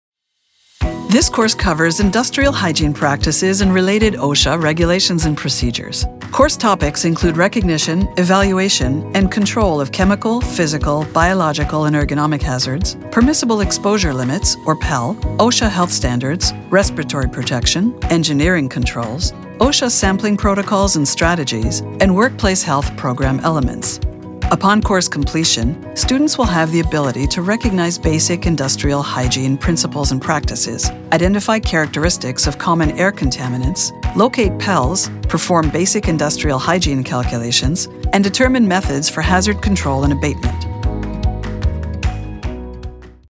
Montreal English / Canadian English
Velvety smooth, luxurious contralto.
Paired with a delivery that is professional and authoritative, the Doctor’s voice is sophisticated, clear, smooth and articulate.